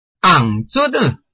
拼音查詢：【饒平腔】zud ~請點選不同聲調拼音聽聽看!(例字漢字部分屬參考性質)